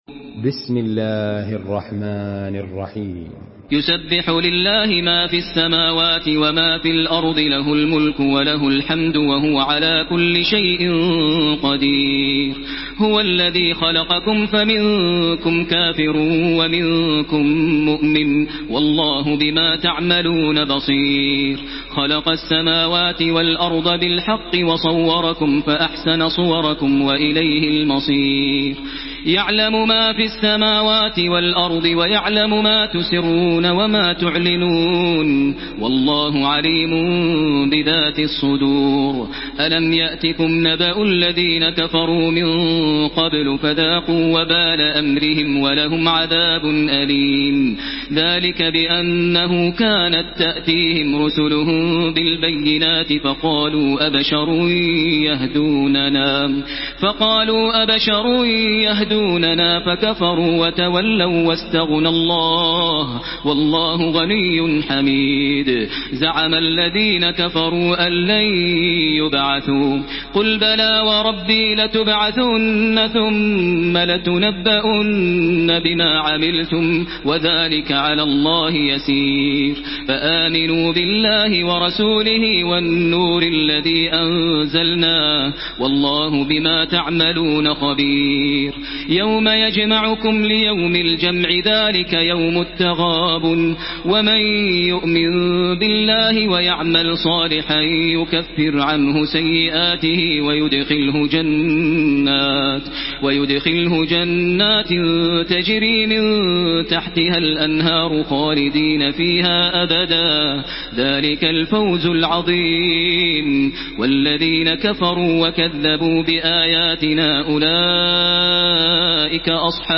Surah At-Taghabun MP3 by Makkah Taraweeh 1433 in Hafs An Asim narration.
Murattal Hafs An Asim